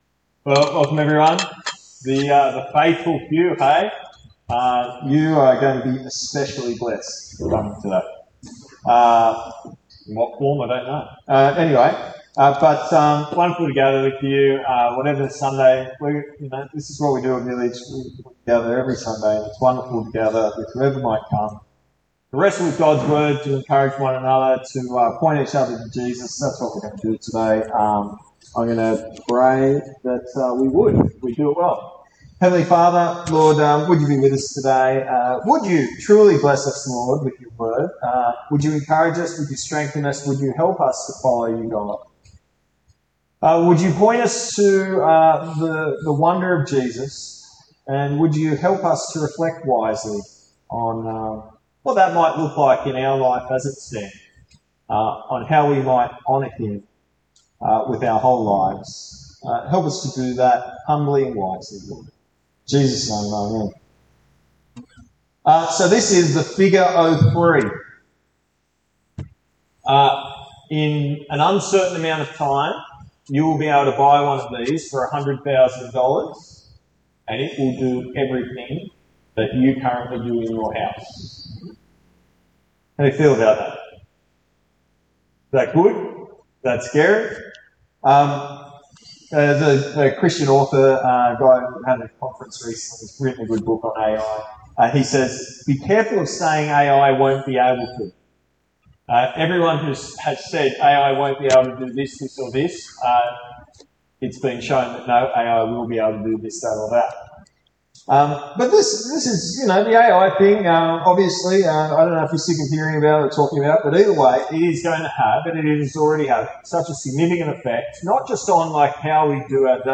What has God made you for and how do you reclaim your purpose? Come listen to today's sermon to find out.